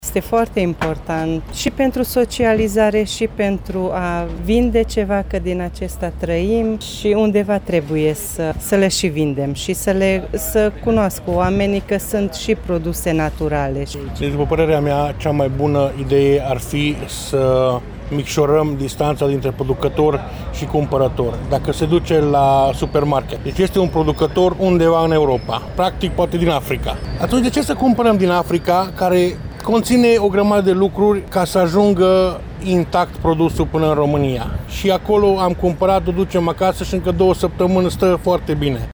Producătorii locali îşi doresc ca târgumureşenii să înţeleagă cât de important este ca lanţul de consum să fie cât mai scurt: